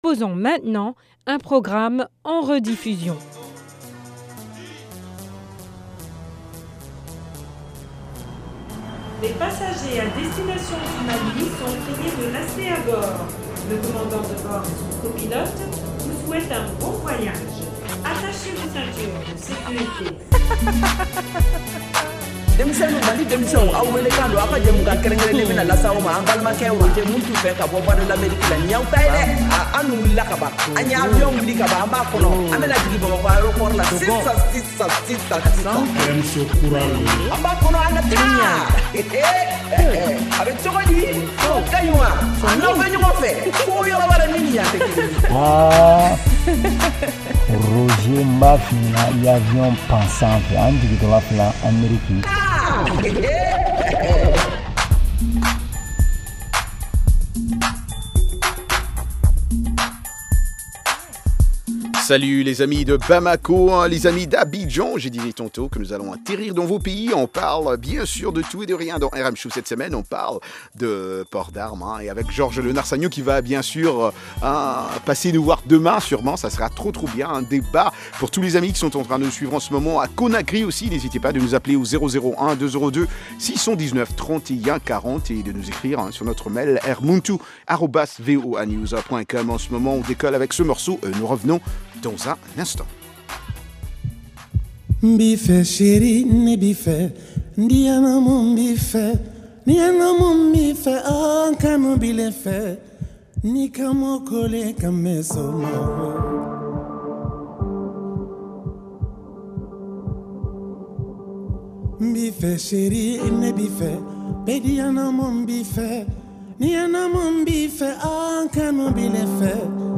Emission quotidienne de musique et d’entretien avec les auditeurs.